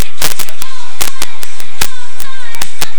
I have a project for a special application of sound collection (200Hz ~ 5 kHz); the specification of sound collection is 50 kSPS, ADC 16 - bit.
Noise is still generated.